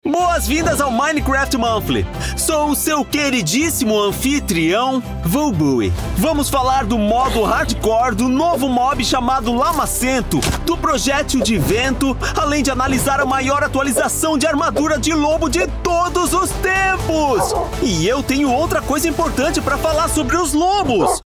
Exato
Articular
Autoritário